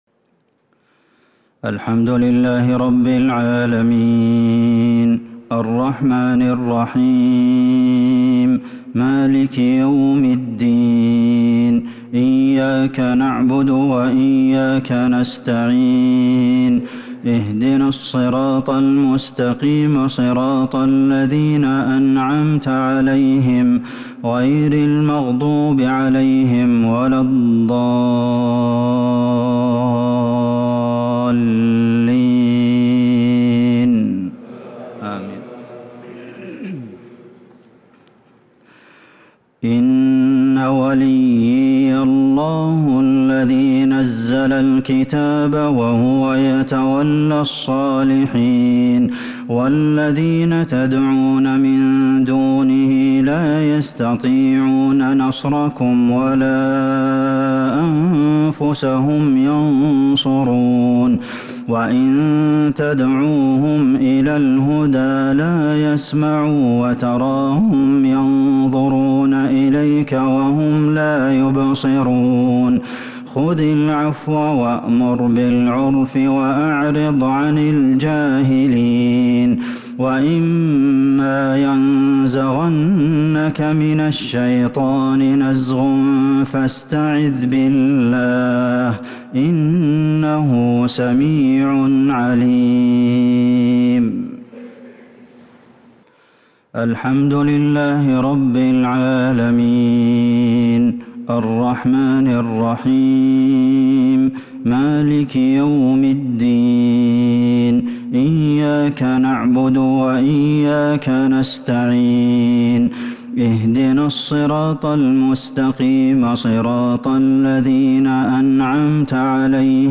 صلاة المغرب للشيخ عبدالمحسن القاسم 9 ذو الحجة 1442 هـ
تِلَاوَات الْحَرَمَيْن .